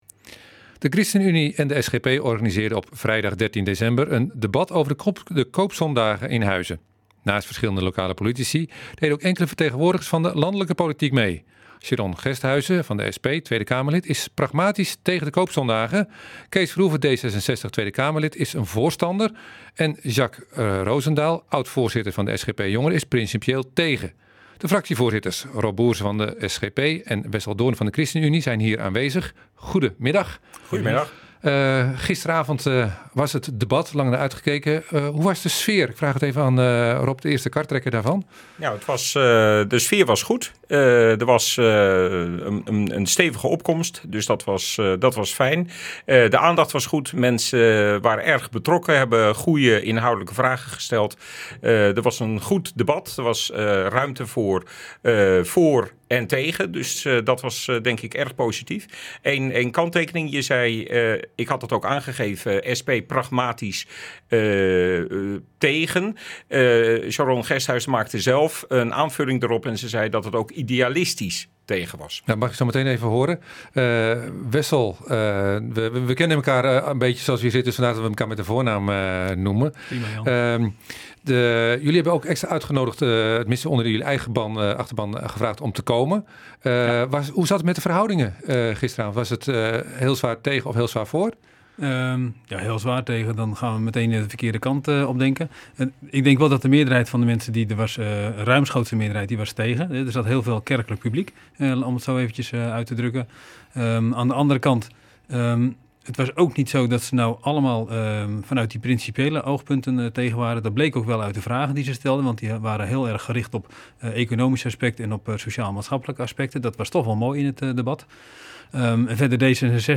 Fractievoorzitters Rob Bource van de SGP en Wessel Doorn van de Christenunie vertel over de vrijdag 13 december gehouden debatavond over de concequenties van het invoeren van de koopzondag in Huizen.